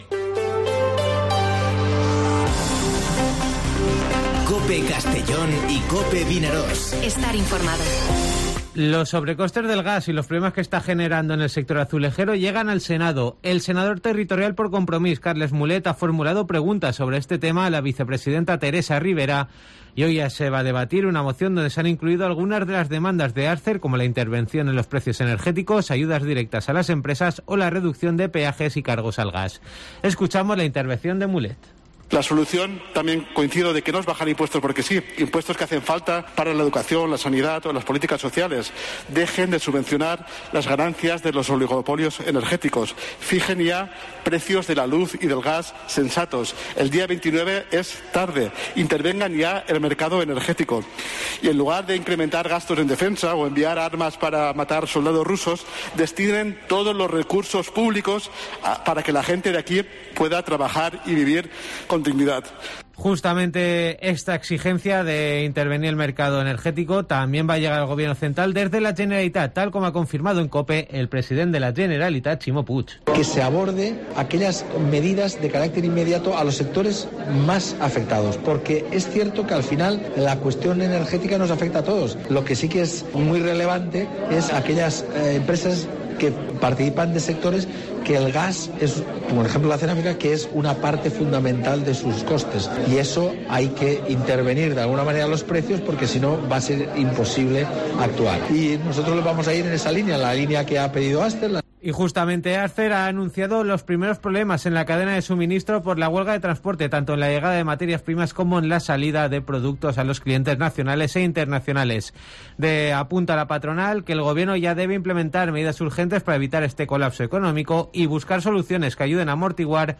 Informativo Mediodía COPE en Castellón (23/03/2022)